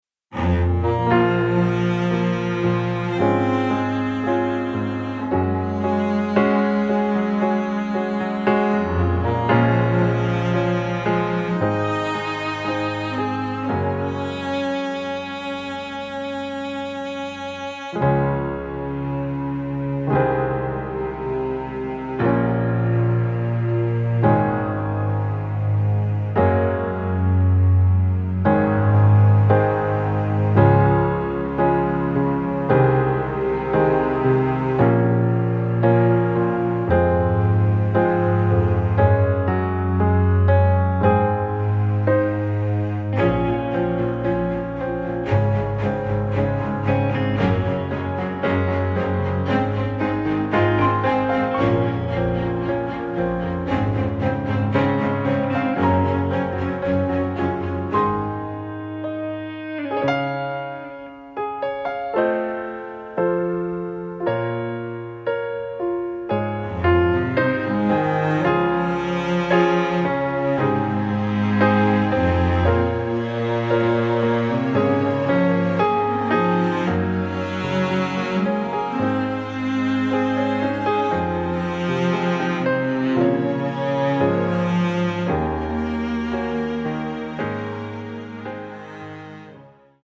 伴奏信息
歌曲调式：升C调